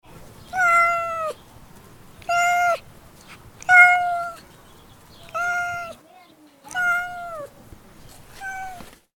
دانلود آهنگ گربه گرسنه و درمانده از افکت صوتی انسان و موجودات زنده
دانلود صدای گربه گرسنه و درمانده از ساعد نیوز با لینک مستقیم و کیفیت بالا
جلوه های صوتی